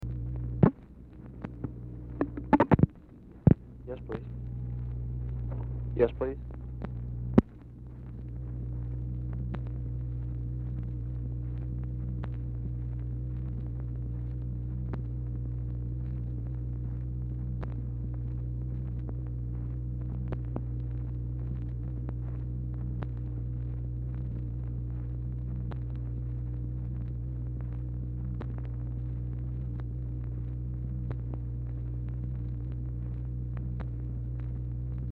Telephone conversation # 1136, sound recording, SIGNAL CORPS OPERATOR, 1/1/1964, time unknown | Discover LBJ
Format Dictation belt
Location Of Speaker 1 LBJ Ranch, near Stonewall, Texas
Specific Item Type Telephone conversation